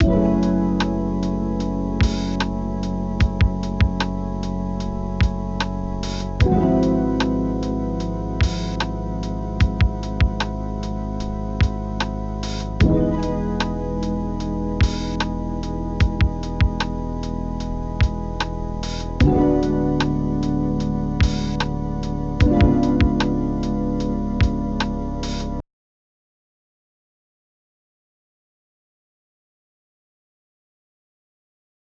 Lo-Fi Wicker Cut 30.wav